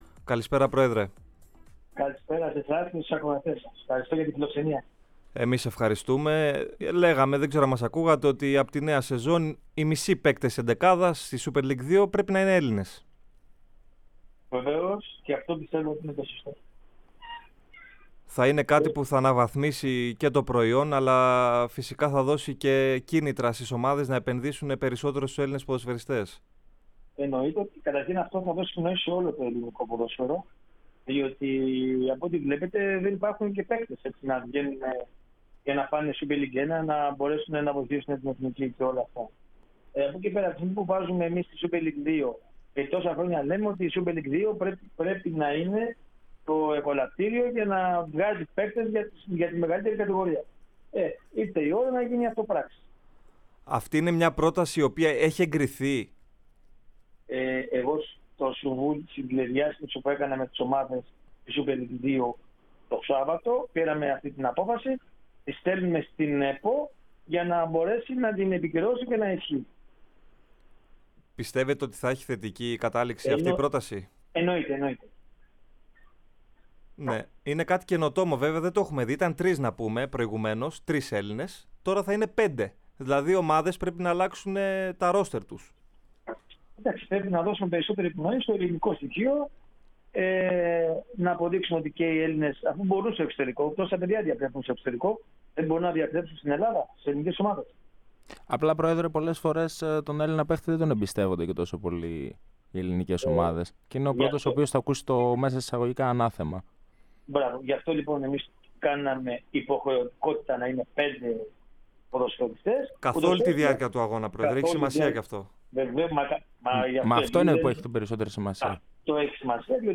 μίλησε στην εκπομπή της ΕΡΑ ΣΠΟΡ